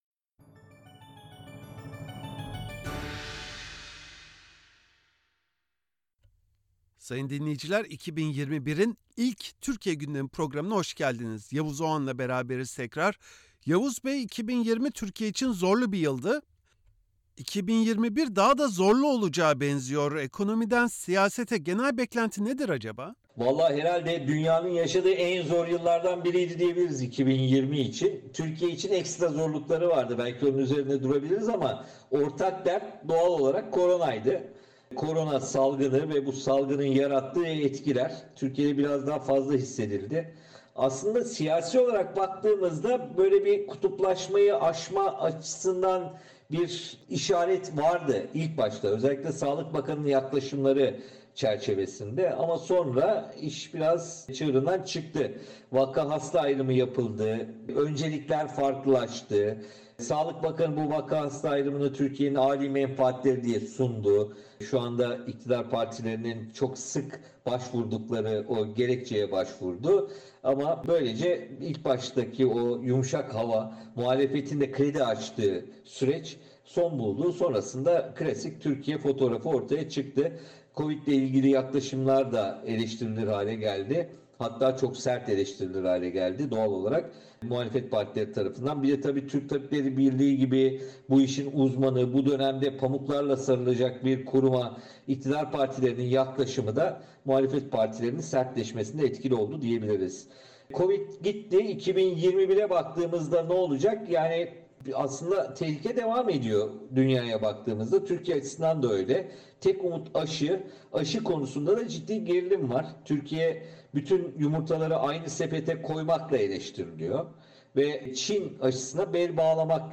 Gazeteci Yavuz Oğhan SBS için Türkiye’de en önemli gelişmeleri ve 2021’den beklentileri değerlendirdi.